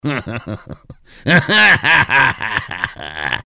Ördögi nevetés csengőhang